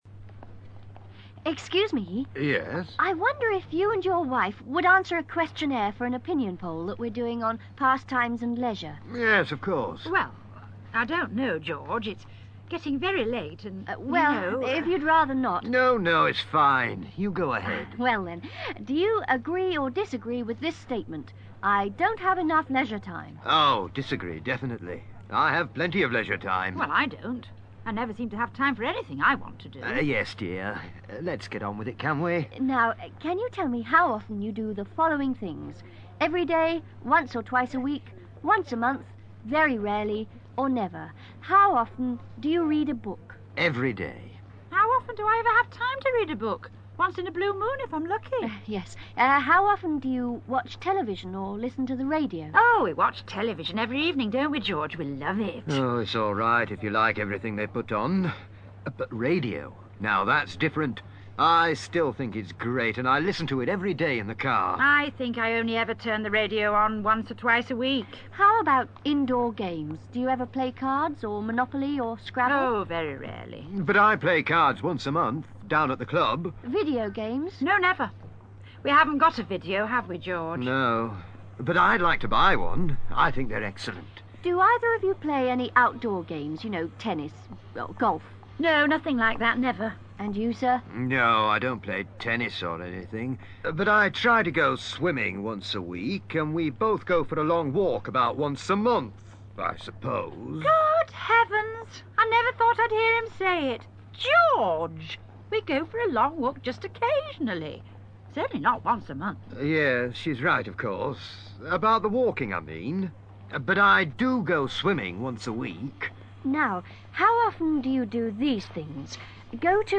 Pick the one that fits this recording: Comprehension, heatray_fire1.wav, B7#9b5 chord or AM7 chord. Comprehension